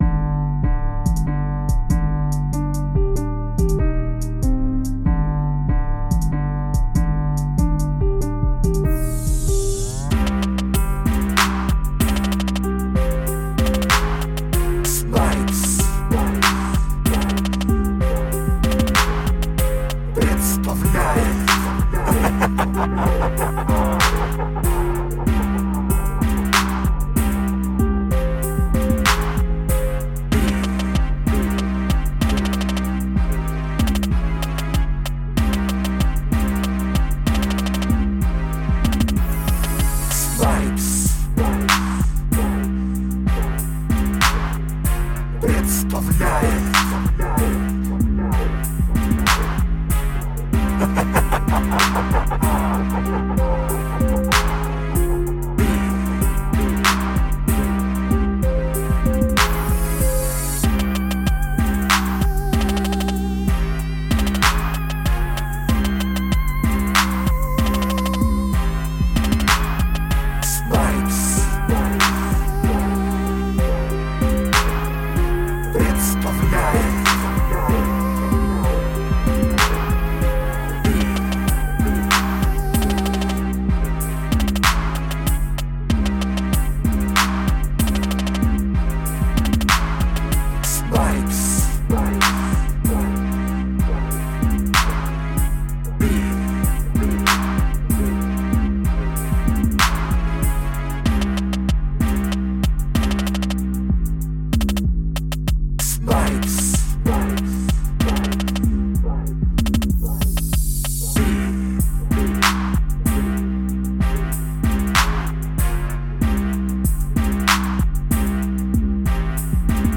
Underground TRap Beat